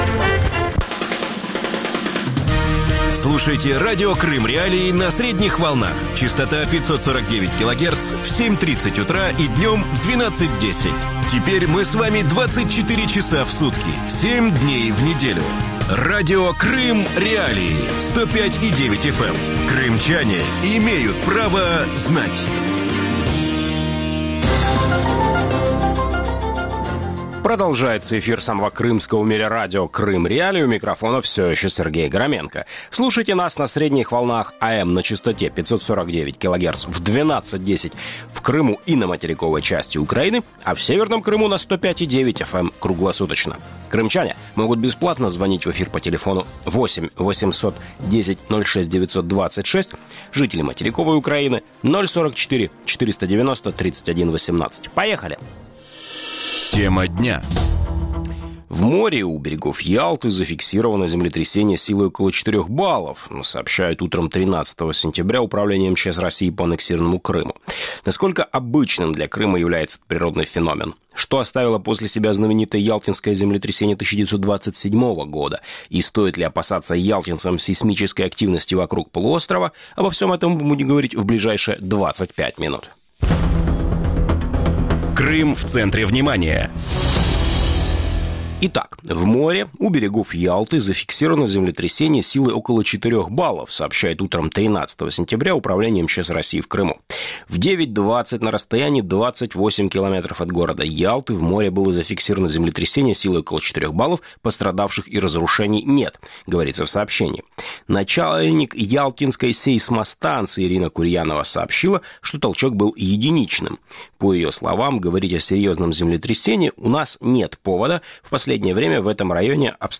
ток-шоу